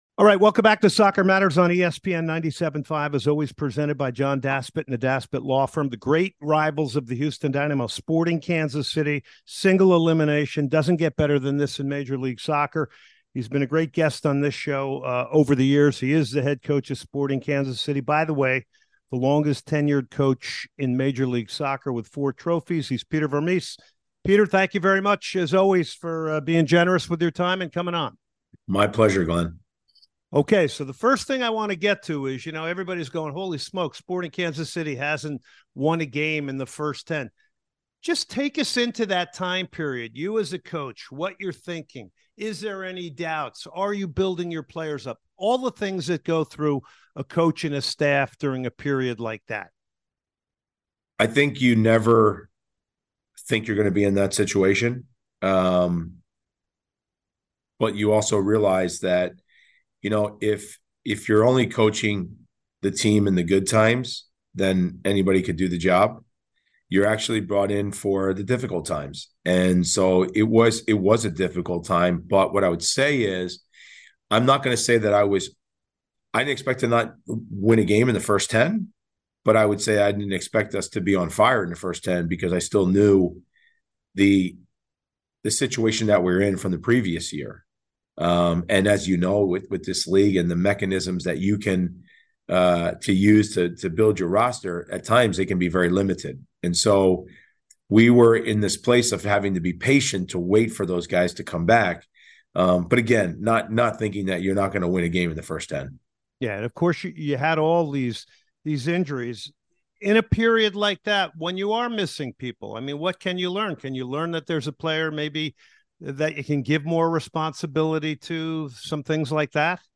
Sporting Kansas City Head Coach Peter Vermes joins the show for an interview ahead of the clash happening next Sunday between the Houston Dynamo and Kansas for the MLS playoffs